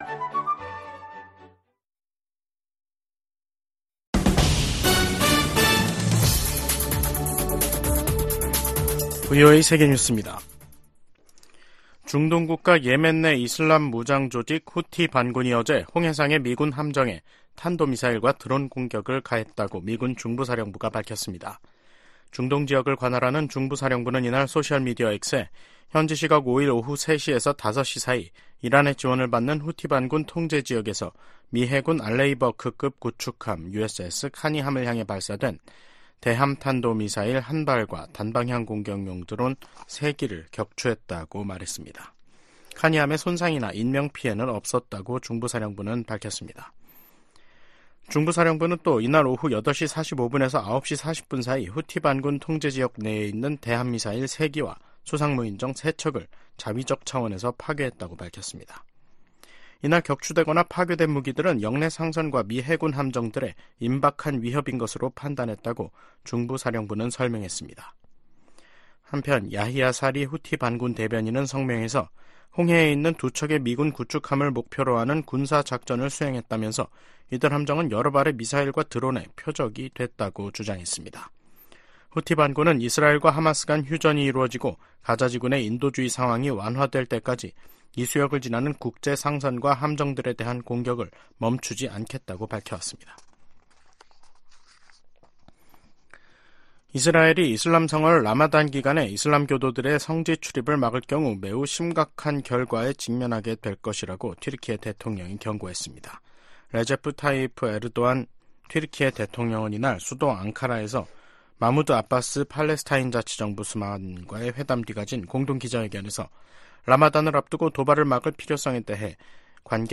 VOA 한국어 간판 뉴스 프로그램 '뉴스 투데이', 2024년 3월 6일 2부 방송입니다. 미 국무부 고위 관리가 북한 비핵화에 중간 단계 조치 필요성을 인정했습니다.